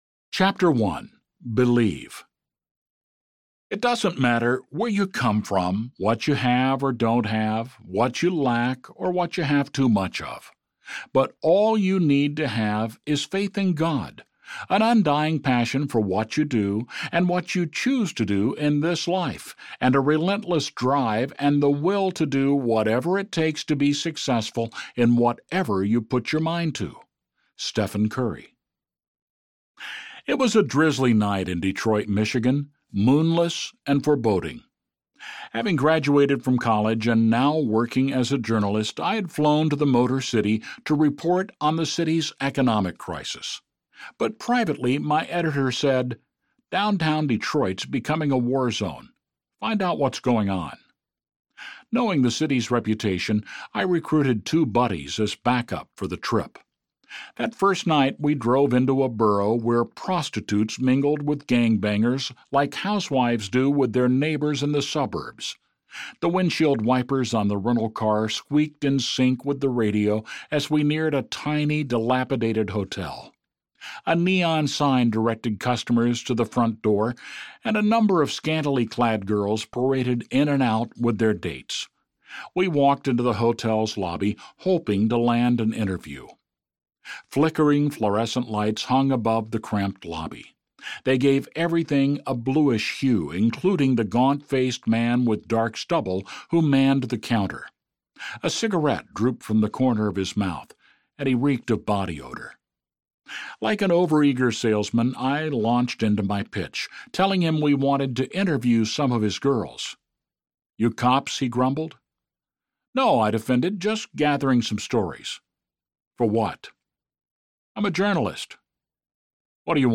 Disruptive Compassion Audiobook
Narrator